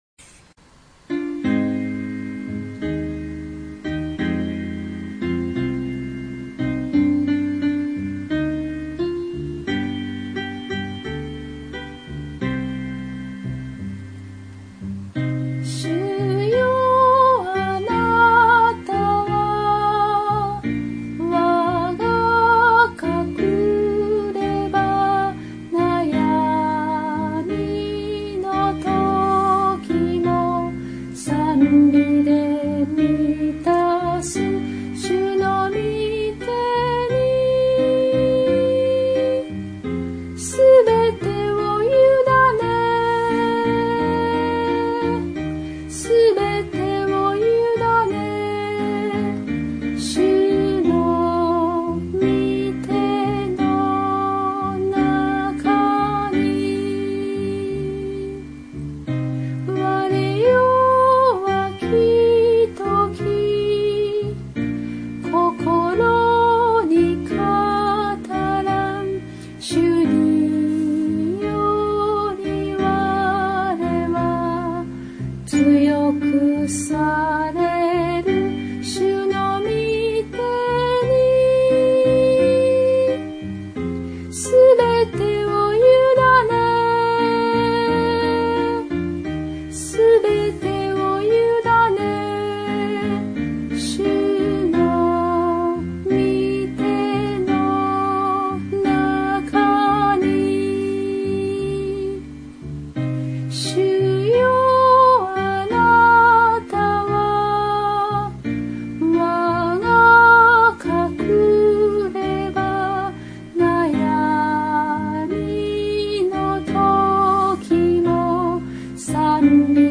徳島聖書キリスト集会讃美集